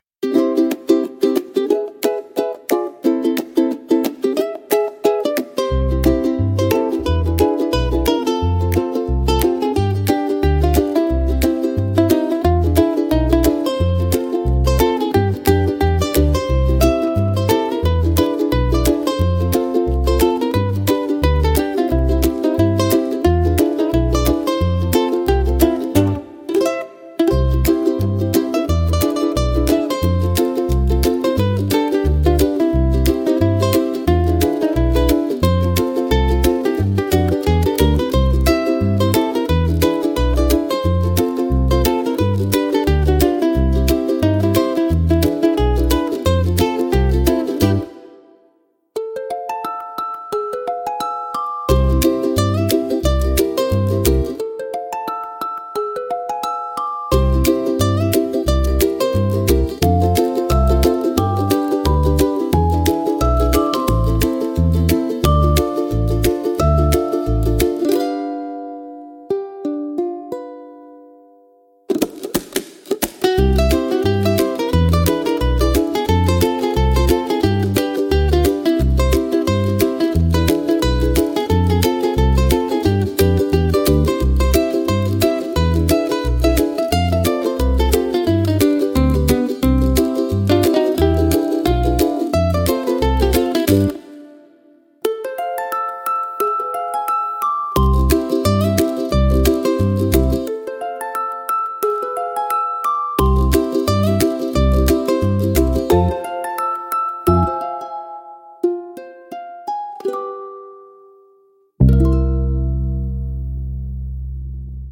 聴く人にゆったりとした安らぎや心地よさを届ける、穏やかで親しみやすいジャンルです。